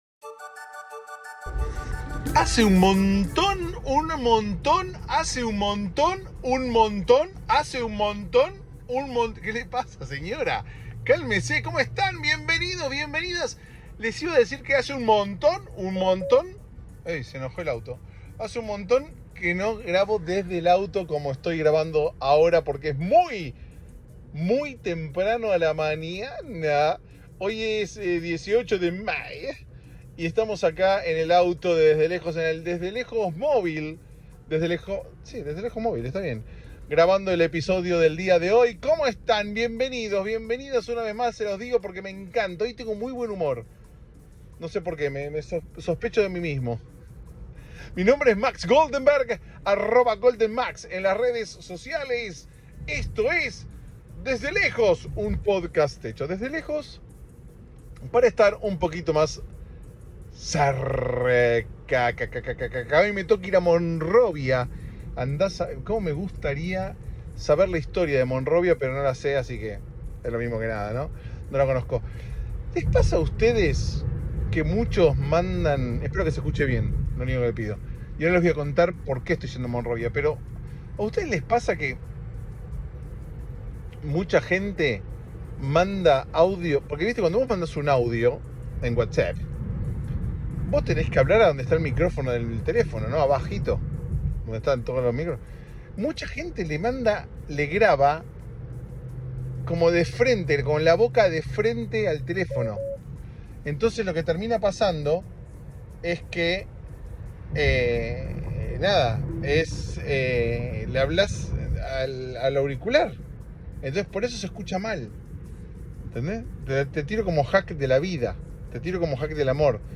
Mirá este día, este momento, esta luna (mentira: es de día) Uno de esos episodios grabados desde el movil y el auto decide ir por donde quiere.